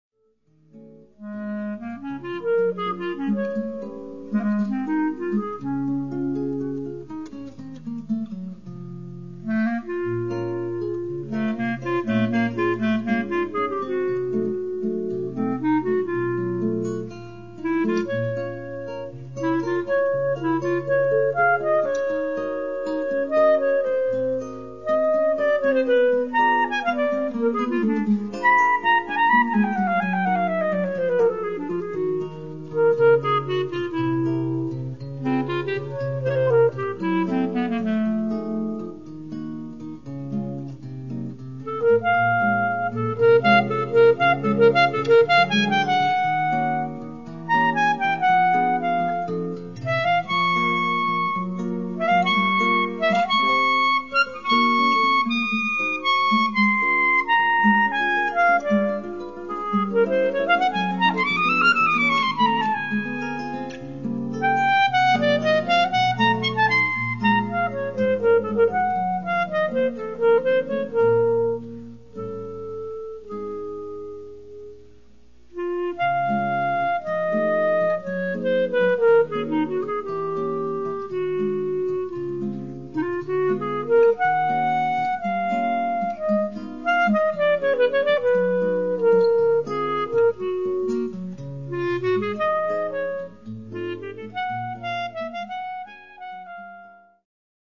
Clarinete
violão e arranjo